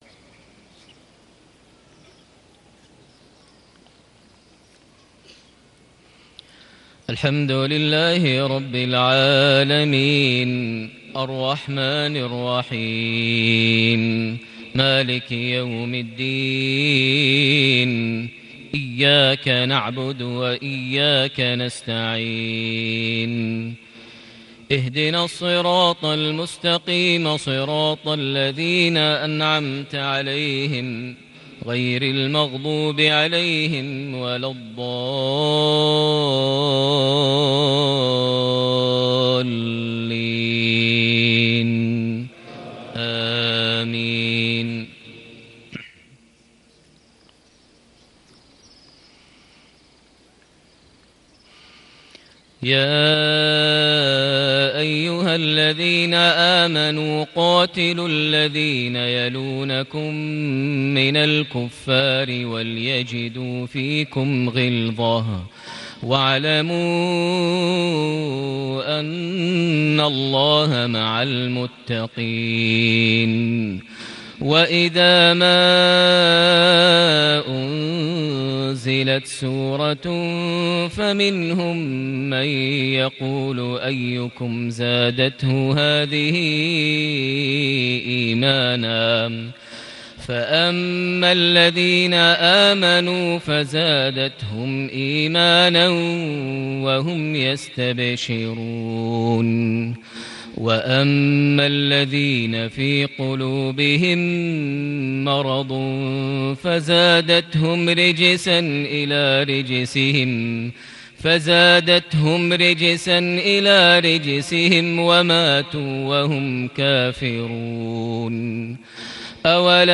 صلاة المغرب ١٩ ربيع الآخر ١٤٣٨هـ خواتيم سورة التوبة > 1438 هـ > الفروض - تلاوات ماهر المعيقلي